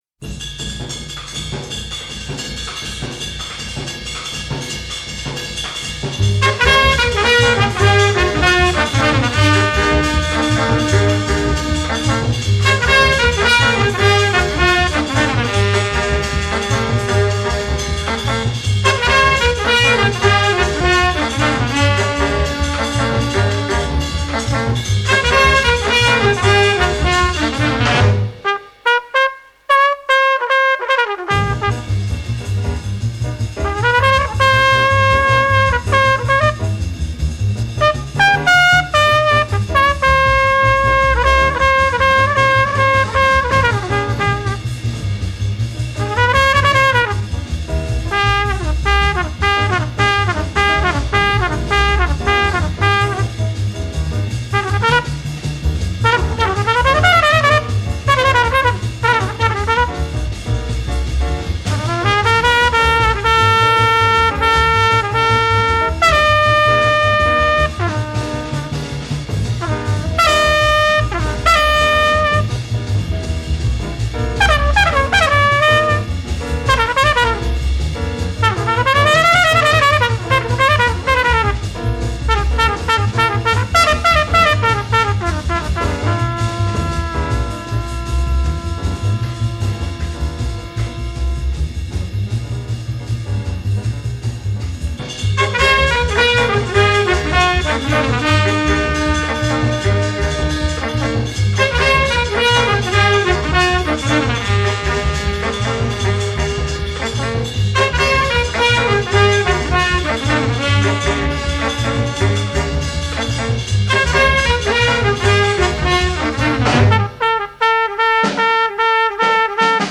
Jazz, Soundtrack